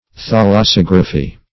Search Result for " thalassography" : The Collaborative International Dictionary of English v.0.48: Thalassography \Thal`as*sog"ra*phy\, n. [Gr. qa`lassa sea + -graphy.] The study or science of the life of marine organisms.